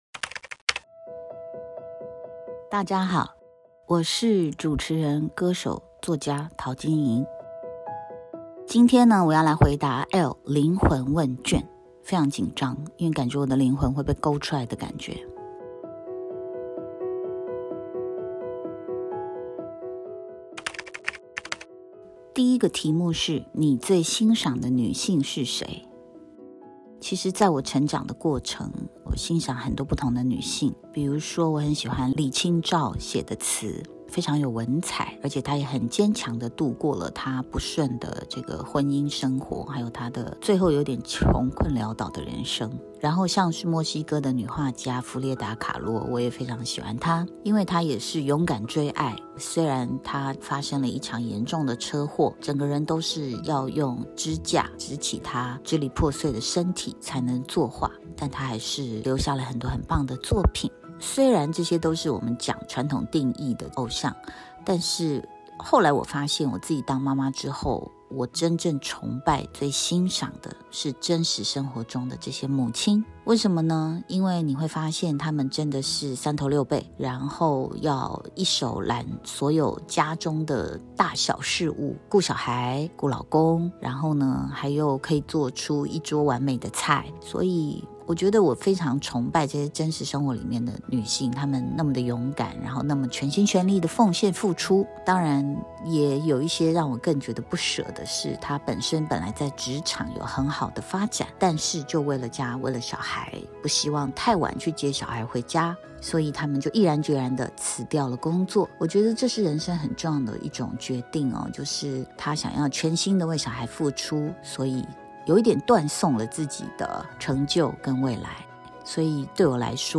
今天，为你带来答卷的是主持人、歌手、作家陶晶莹。